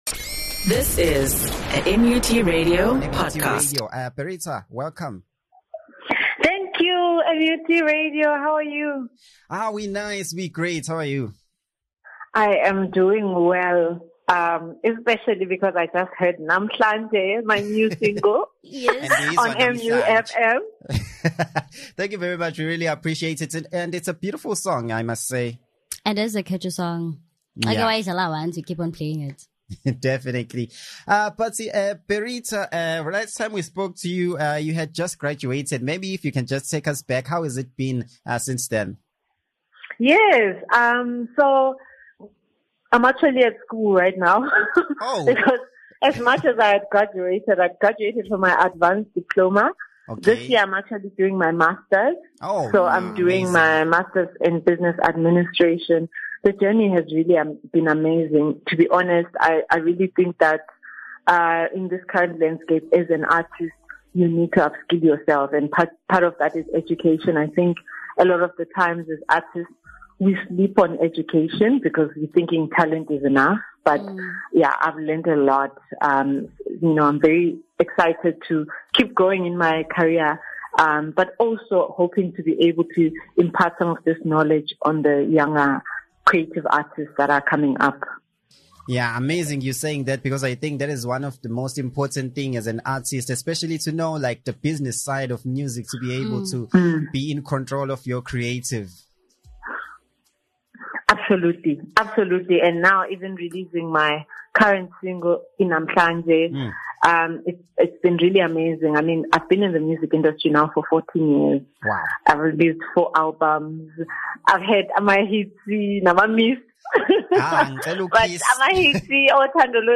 They unpacked the inspiration behind her latest single, Namhlanje, and what it means to her. The interview offered a deeper look into her artistry, passion, and the message behind her music.